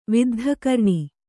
♪ viddha karṇi